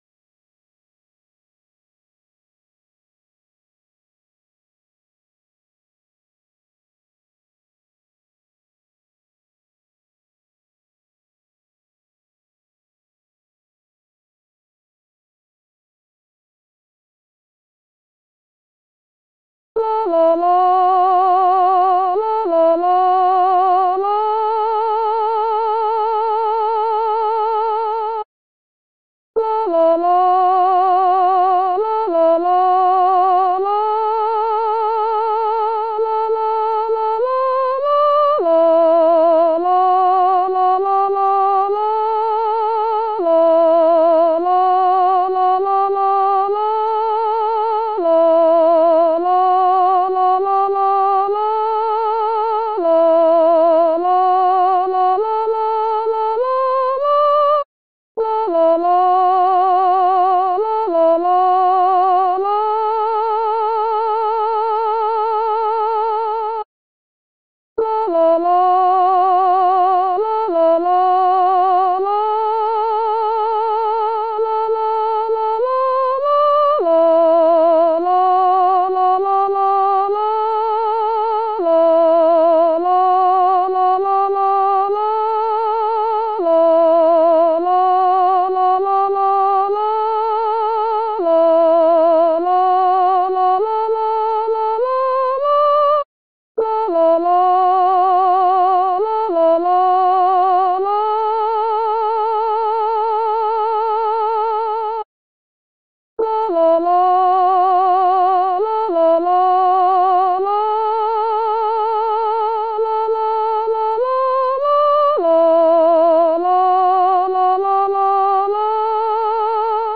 Coro musica afroamericana, blues, swing, spirituals - Bologna
soprani